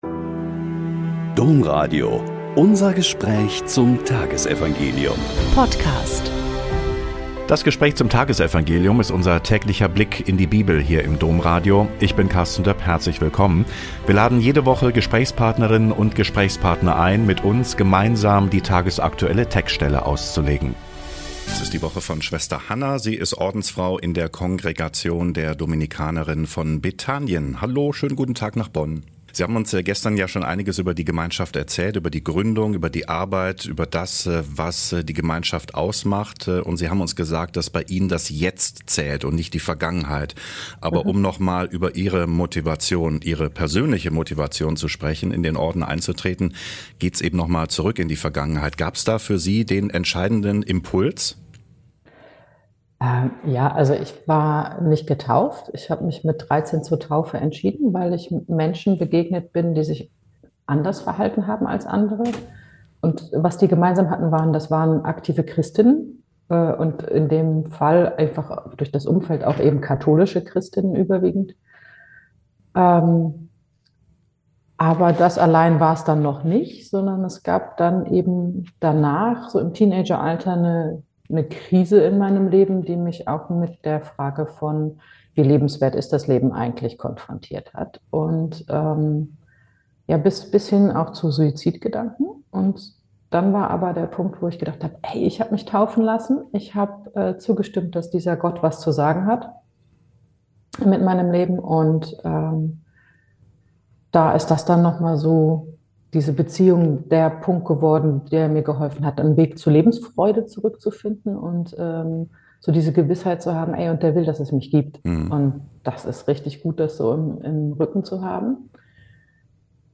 Mk 9,2-10 - Gespräch